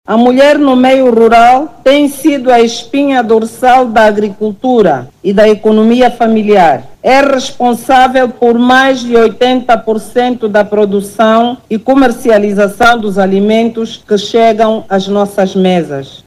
Os dados foram apresentados pela ministra da Acção Social, Família e Promoção da Mulher, Ana Paula da Silva do Sacramento Neto, durante a abertura do XII Fórum Nacional da Mulher no Meio Rural, que decorreu na província do Huambo.
01-ANA-PAULA-DA-SILVA-DO-SACRAMENTO-NETO-FORUM-MULHER-RUAL-13HRS.mp3